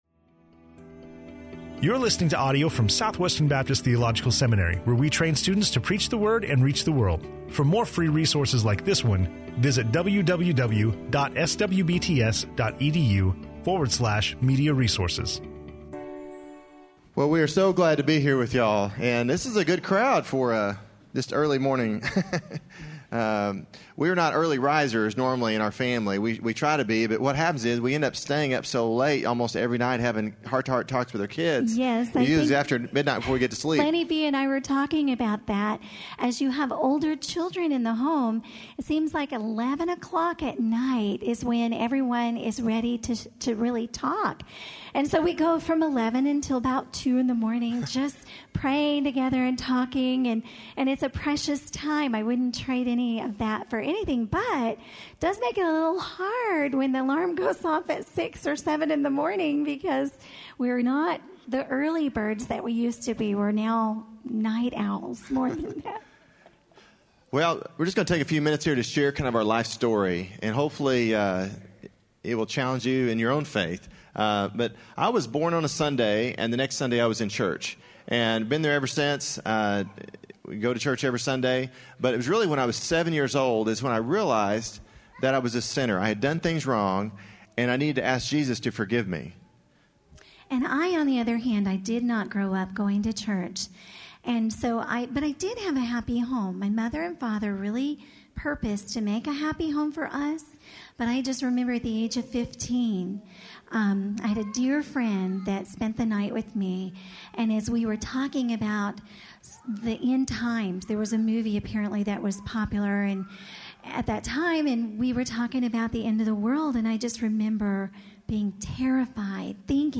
Jim Bob Duggar speaking on in SWBTS Chapel on Thursday October 24, 2013